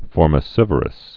(fôrmĭ-sĭvər-əs)